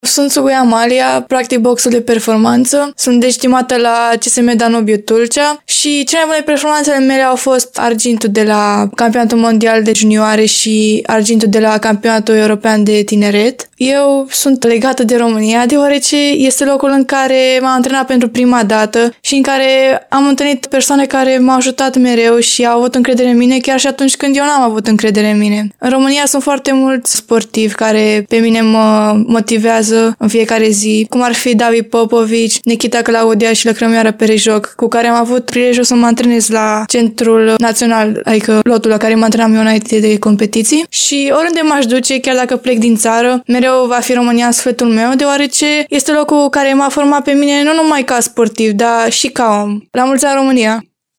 De Ziua Națională, continuăm să vă aducem mărturii ale unor tineri care își trăiesc vocația chiar aici, în România.